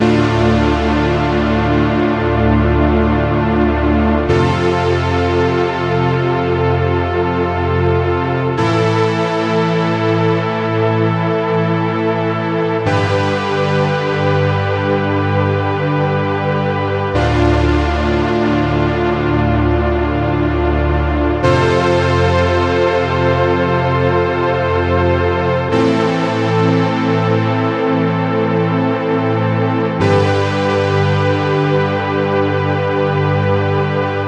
描述：来自ensoniq esq 112bpm的一些倒置和弦
Tag: 环境 ENSONIQ ESQ